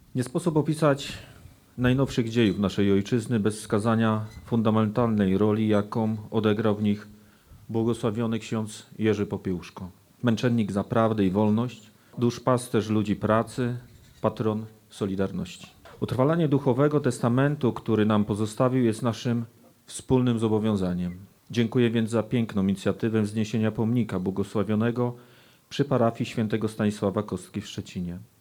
Premier Mateusz Morawicki skierował list do uczestników uroczystości. Treść listu odczytał Wojewoda Zachodniopomorski – Zbigniew Bogucki.